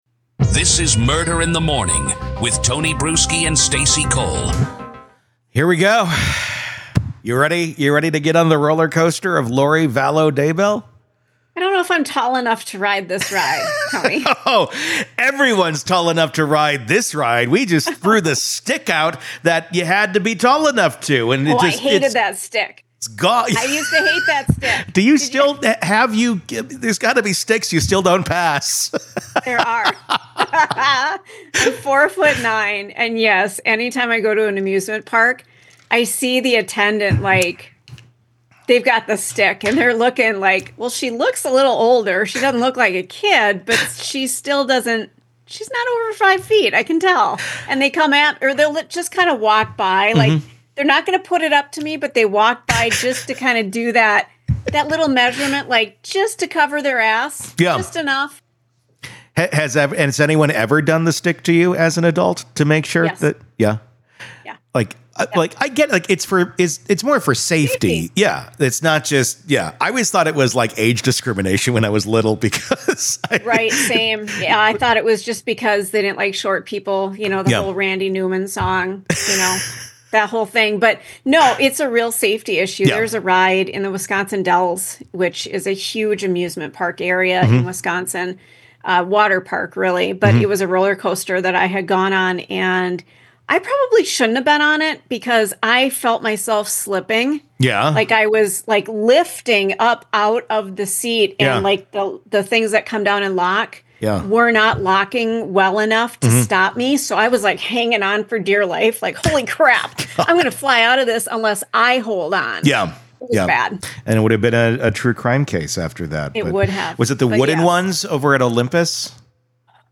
Lori Daybell: The Cult Mom Returns to Court- Prosecution Opening Statements In Full